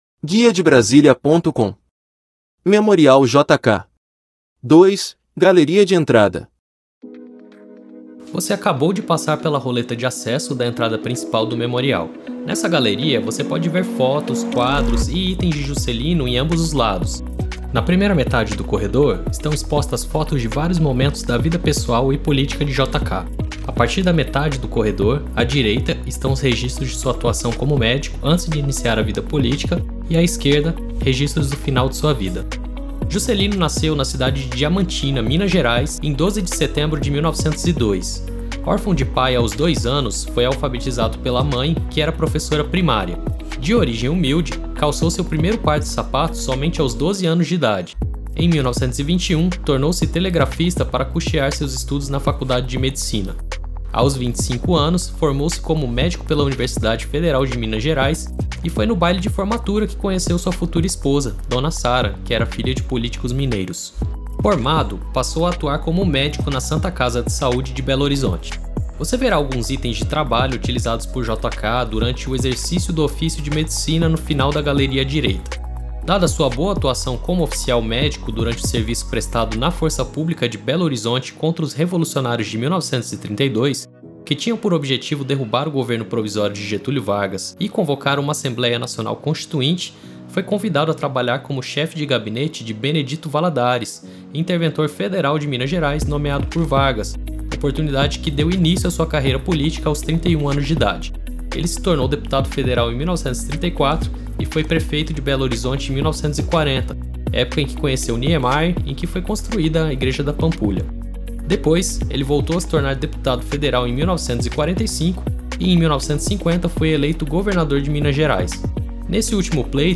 Audioguia